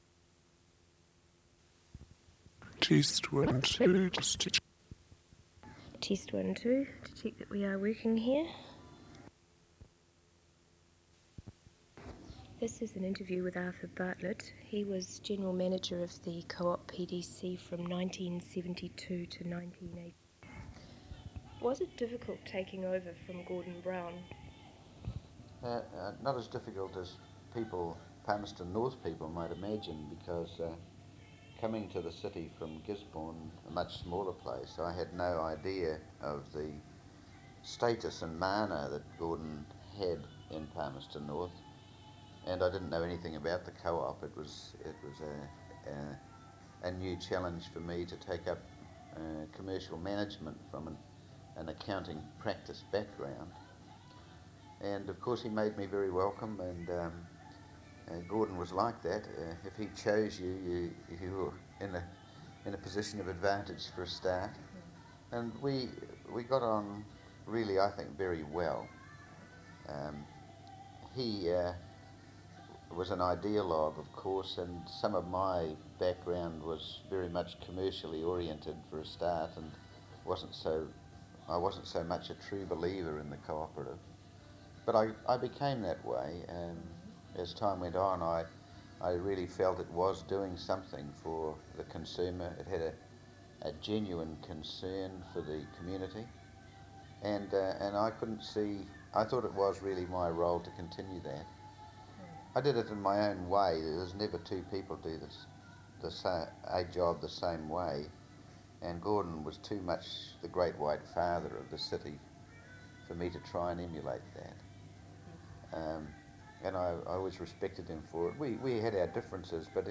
Oral Interview - Consumers' Co-operative Society (Manawatu) Ltd
Interviews were made during the Reunion of former staff members.